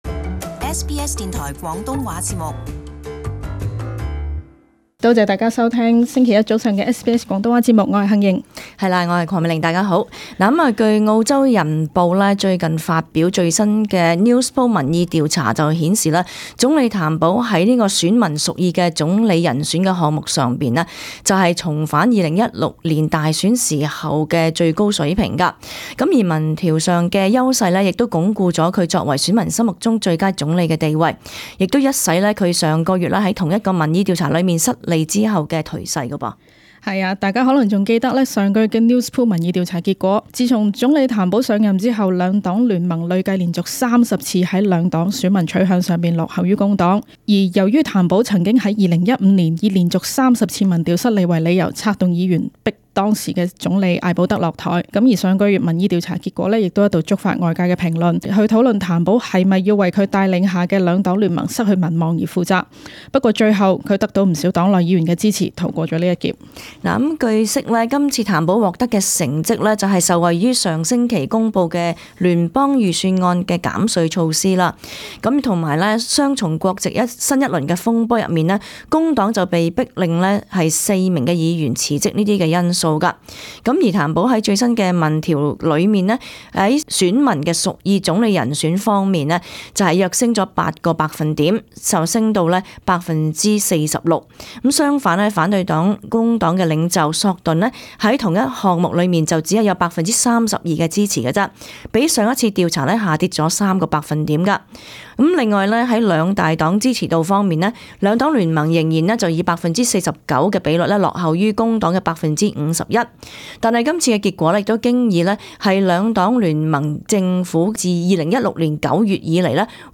【時事報導】Newspoll民調：譚保民望回升至大選時水平